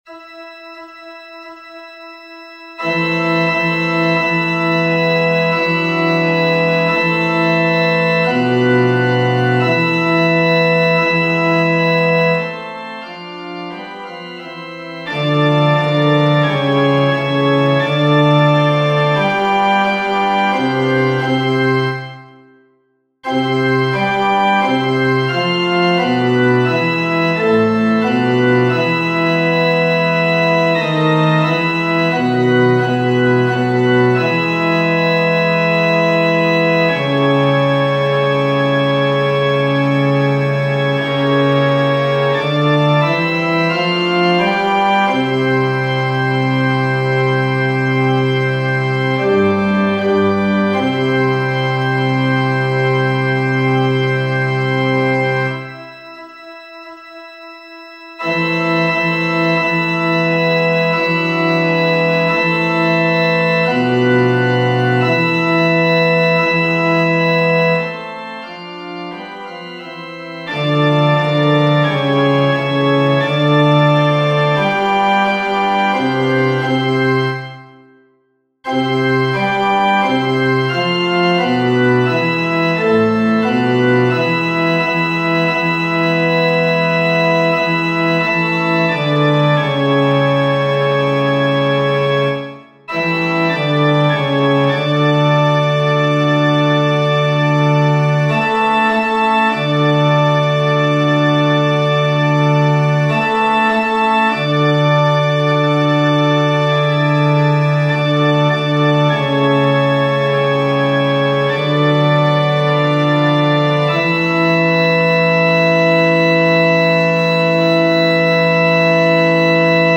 FF:HV_15b Collegium male choir
Modlitba-bas.mp3